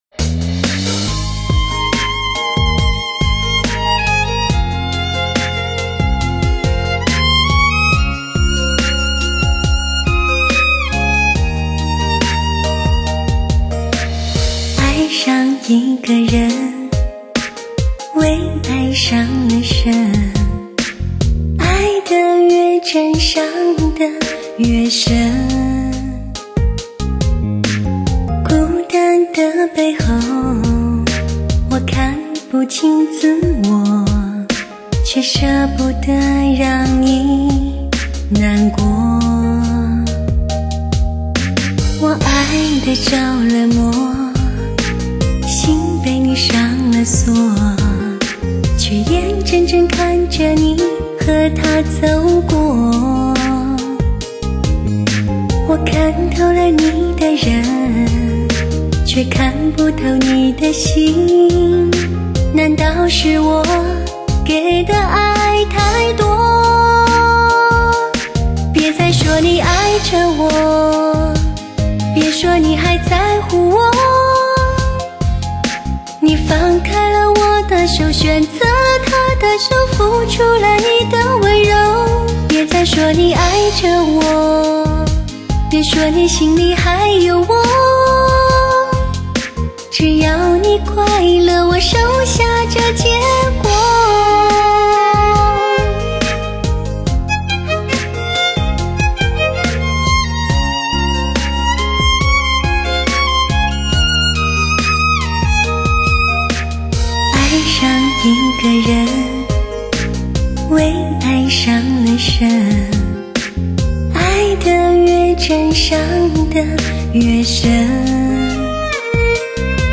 优美动听的声线诠释一种无暇的音乐境界
经典与流行融入发烧合成效果，使歌曲重新换颜